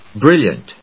音節bril・liant 発音記号・読み方
/bríljənt(米国英語)/